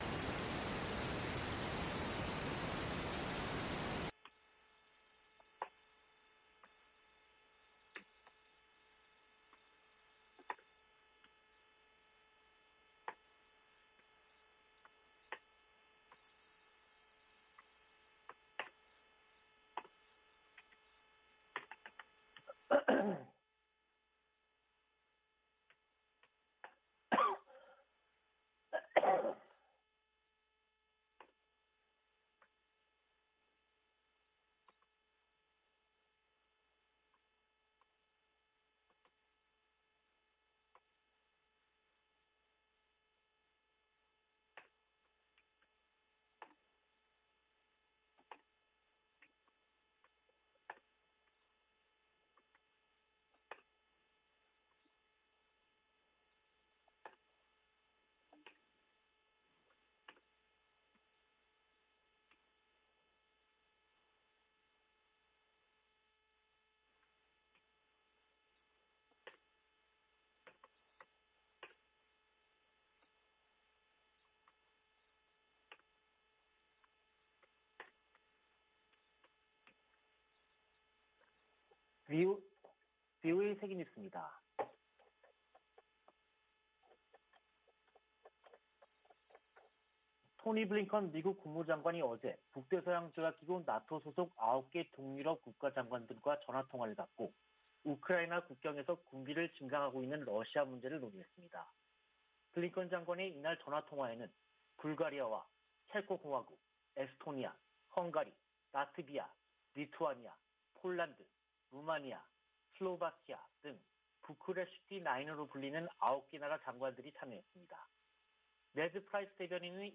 VOA 한국어 간판 뉴스 프로그램 '뉴스 투데이', 2022년 1월 4일 1부 방송입니다. 미 국무부 동아시아태평양국이 조 바이든 행정부 들어 대북 외교에서 역할을 복원 중이라는 감사보고서가 나왔습니다. 미국 정부가 북한에 대화 복귀를 거듭 촉구했습니다. 주요 핵무기 보유국들이 핵무기 사용에 반대하고, 핵확산금지조약(NPT)의 의무를 강조하는 공동성명을 발표했습니다.